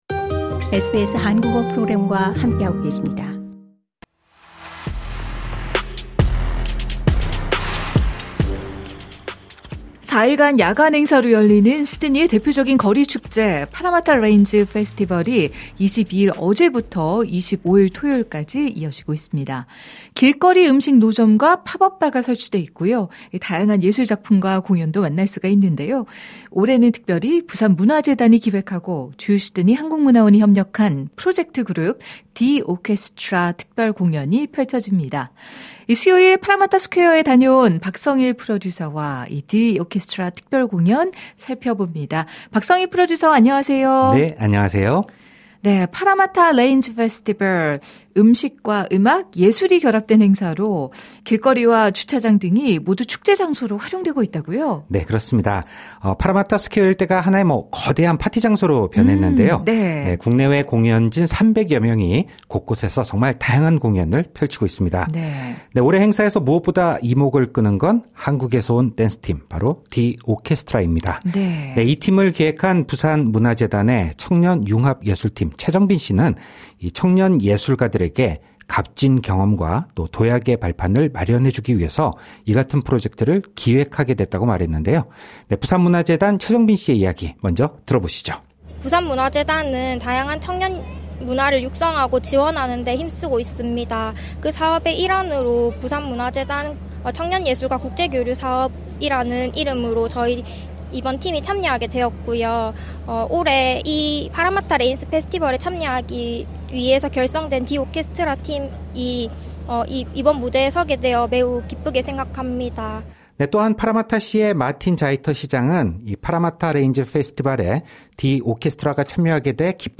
현장 취재: 거리 축제 ‘파라마타 레인즈’ 빛낸 한국 힙합댄스 그룹 ‘D 오케스트라’